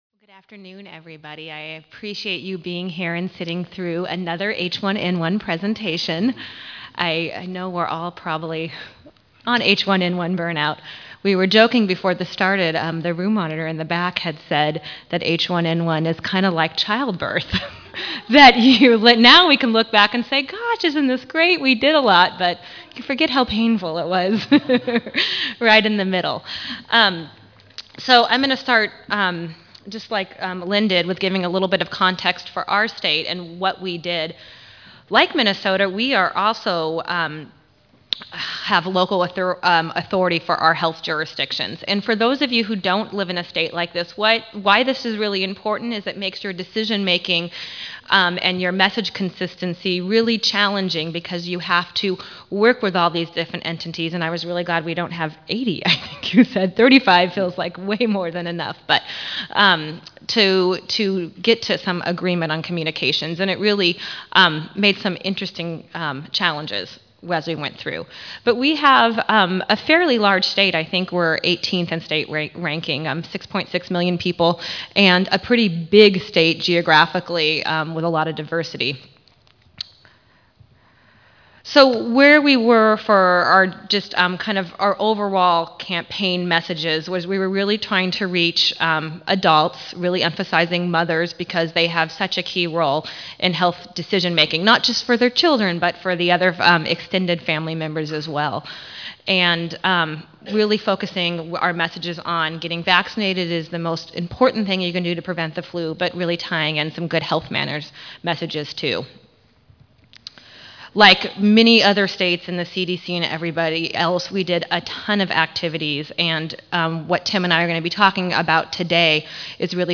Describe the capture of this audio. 44th National Immunization Conference (NIC): Communication and Partnership Lessons Learned during the 2009 H1N1 Influenza Response: A State Perspective